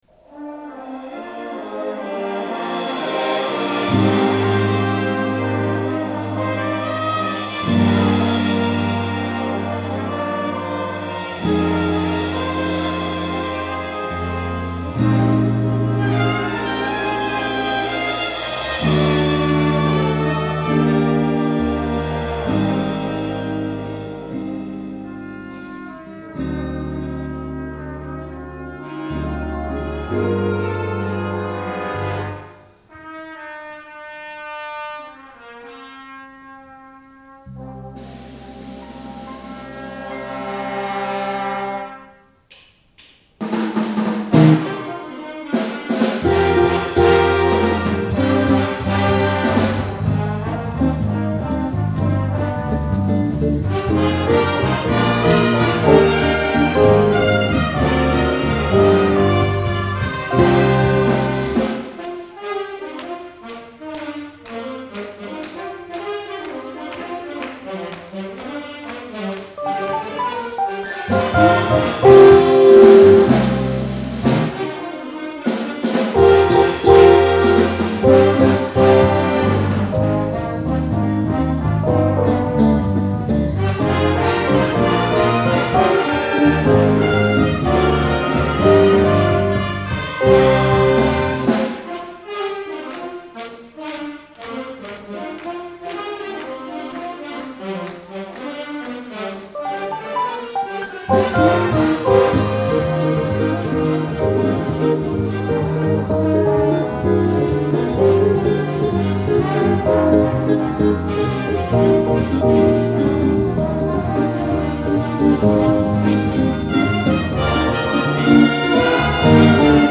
SILVER SOUNDS JAZZ ORCHESTRA (on GUITAR)
Spain (1994 Rehearsal)